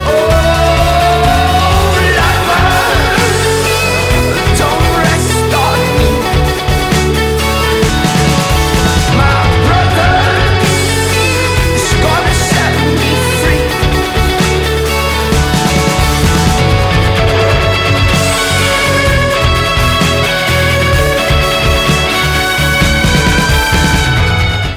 • Качество: 192, Stereo
Интересное сочетание рока и звуков скрипки